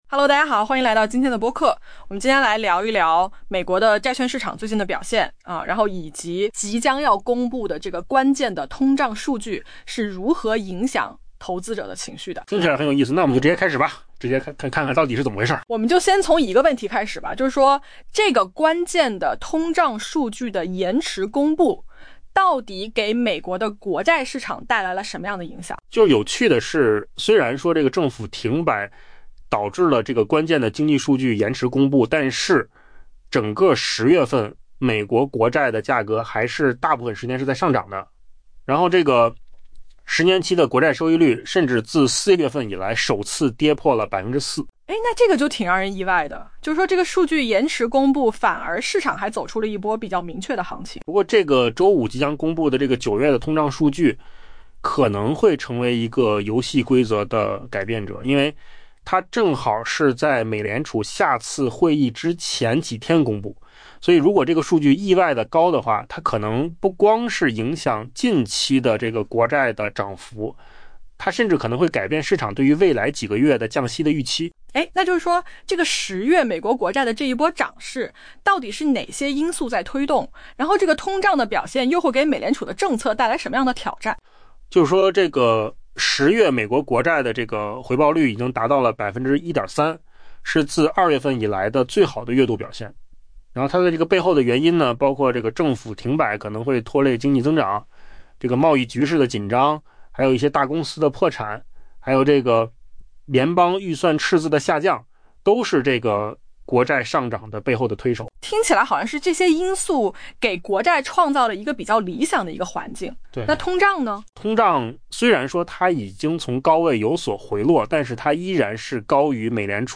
AI 播客：换个方式听新闻 下载 mp3 音频由扣子空间生成 缺乏数据指引的美国债券交易员，可能眼睁睁看着十月以来的国债涨势被他们苦等多时的关键通胀数据毁于一旦。